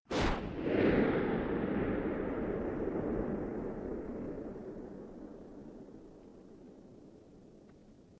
19th Century Cannon
19th century cannon fire
Cannon619thcentury.mp3